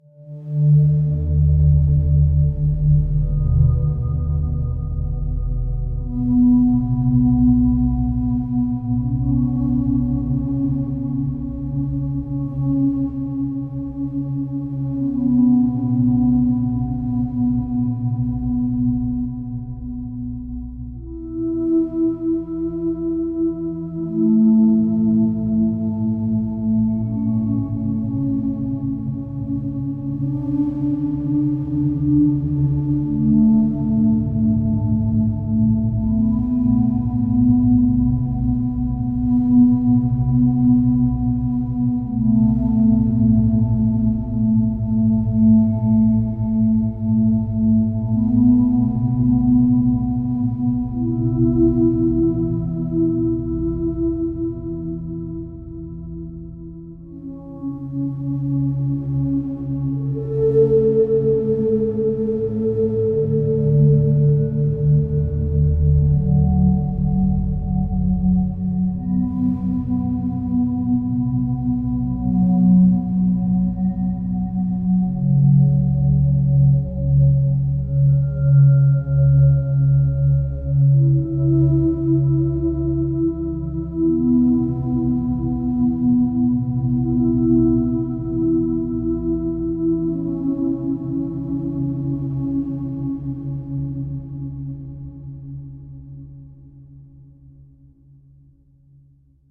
Ambient coming from my Korg NTS-1 Mk2, sequenced with the PGB-1.